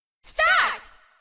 Play Mario Party Start Voice - SoundBoardGuy
Play, download and share Mario party Start voice original sound button!!!!
mario-party-start-voice.mp3